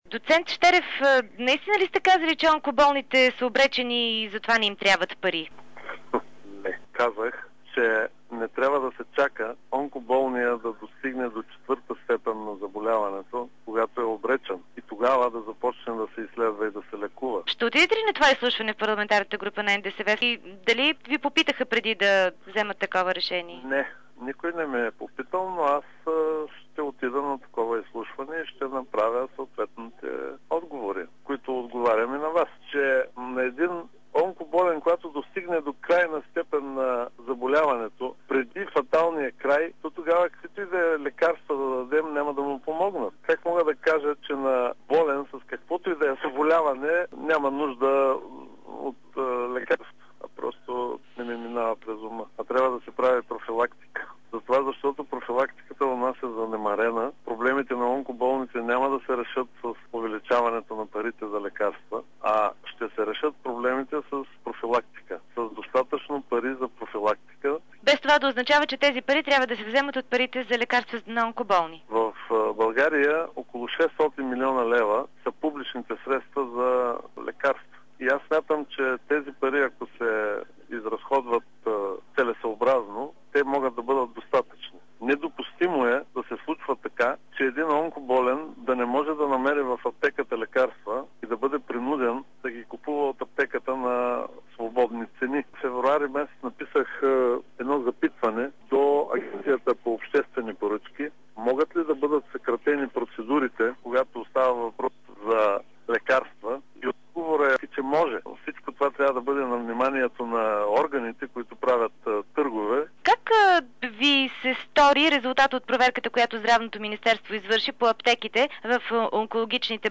Зам.-председателят на здравната комисия Атанас Щерев в интервю